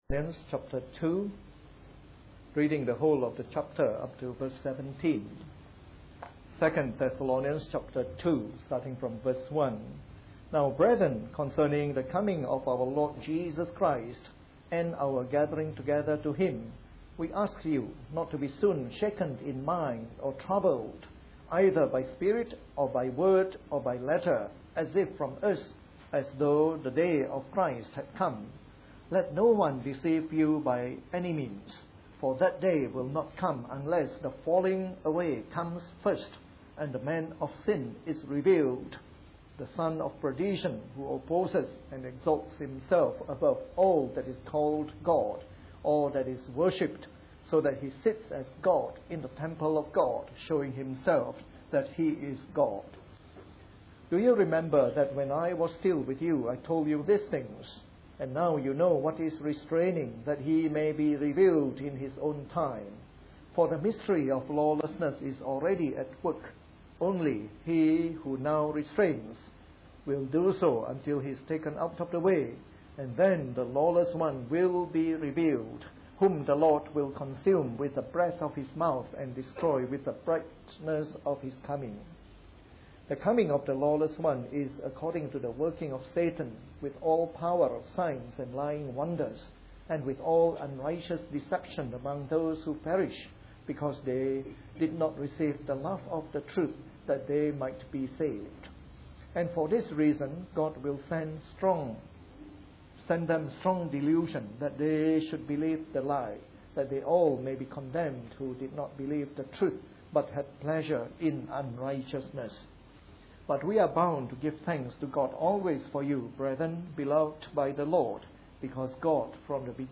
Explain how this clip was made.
A sermon in the morning service from our series on 2 Thessalonians.